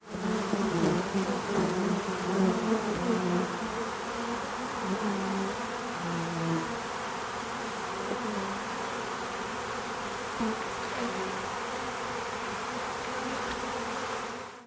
Beehive, Many Bees